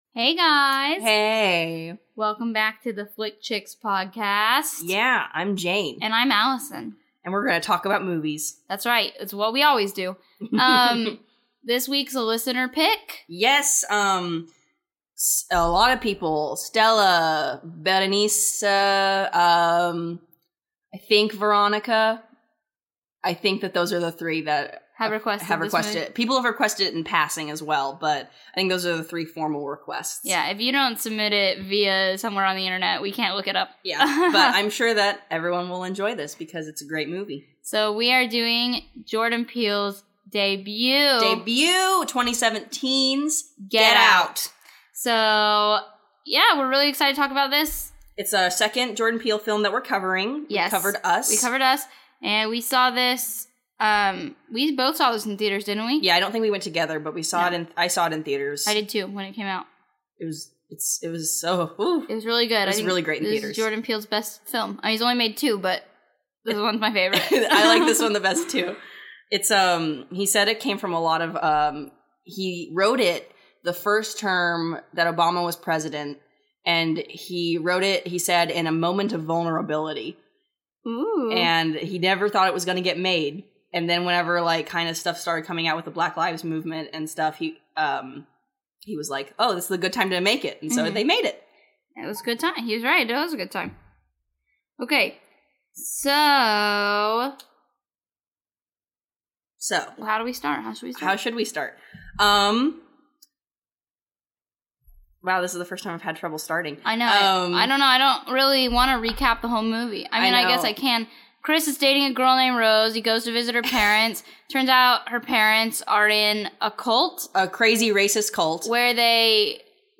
Both of us are white females, and are not the authority on what it is to be a POC, whatsoever.